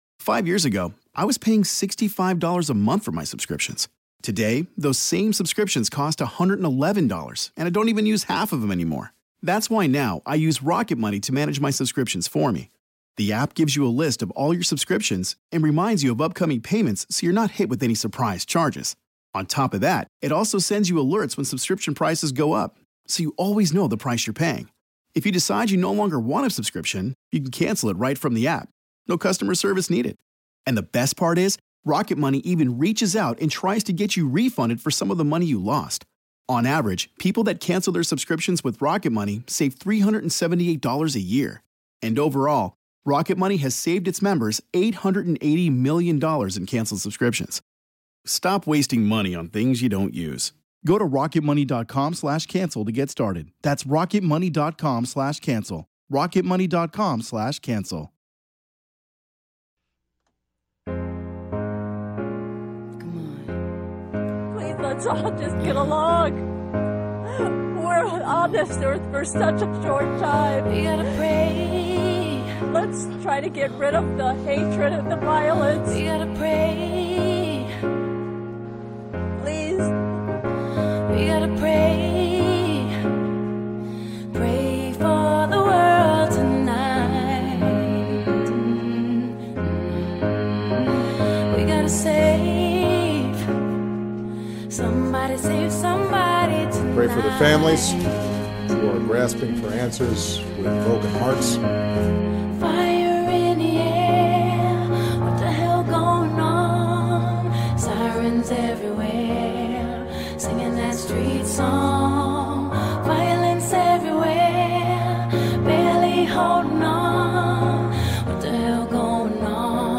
Hear moving audio from family members and witnesses at the Pulse nightclub FBI agents report on the shooter's background